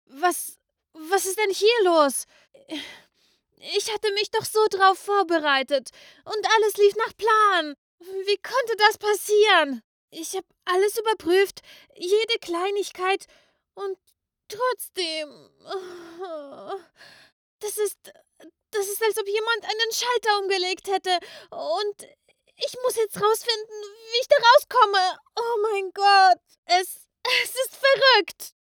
Sprechprobe
Stimmalter: ca. 13-33 Jahre
Stimmfarbe: klar, weich, warm, energisch, zart, verspielt, frech, überdreht, freundlich, begeistert.
Aufnahmezimmer mit geschlossener Sprechkabine
Überrascht/Durcheinander